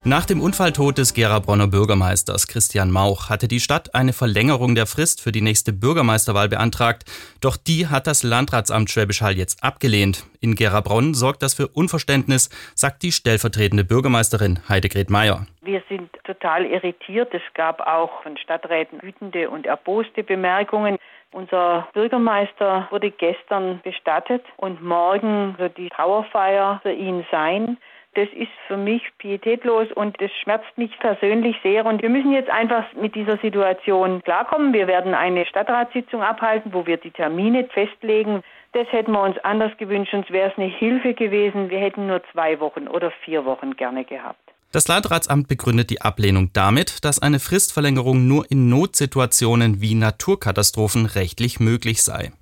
"Das ist für mich pietätlos und schmerzt mich persönlich sehr", sagte die stellvertretende Bürgermeisterin Heidegret Mayer, im SWR-Interview.